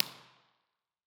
ir_odd_near.wav